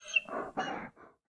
Minecraft Version Minecraft Version 1.21.5 Latest Release | Latest Snapshot 1.21.5 / assets / minecraft / sounds / mob / panda / worried / worried3.ogg Compare With Compare With Latest Release | Latest Snapshot